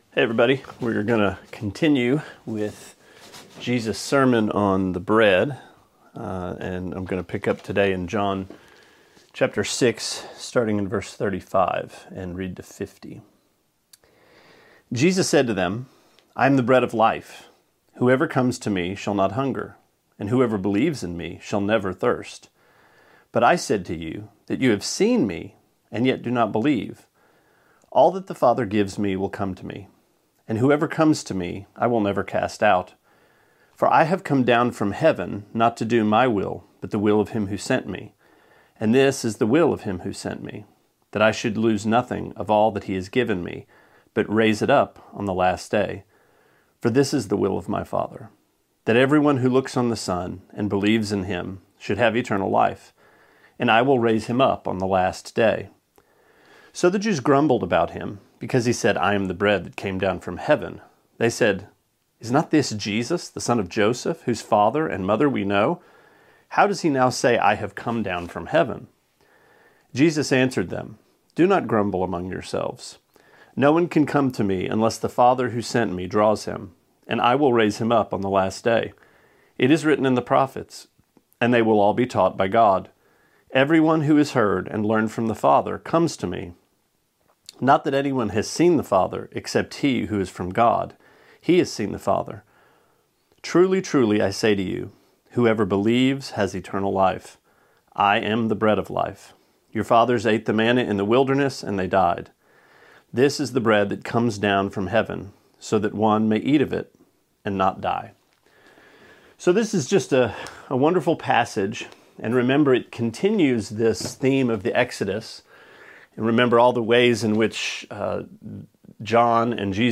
Sermonette 5/5: John 6:35-50: Simply Come